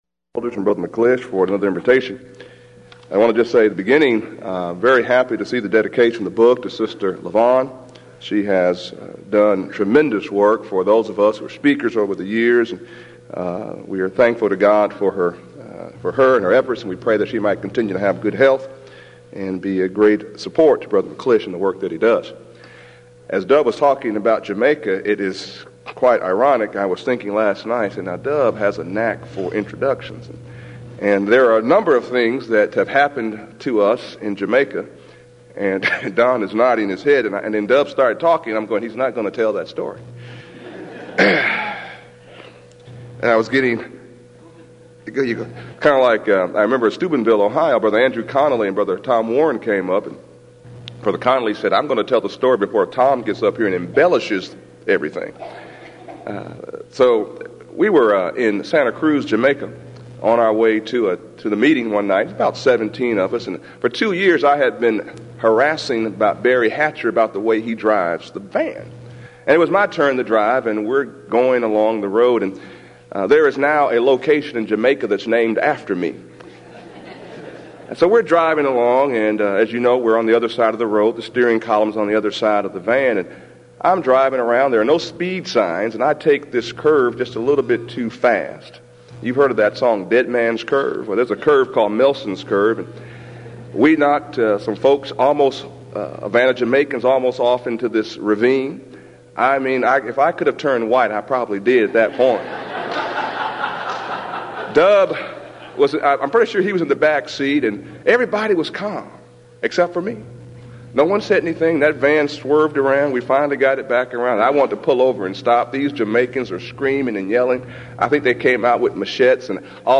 Event: 16th Annual Denton Lectures
lecture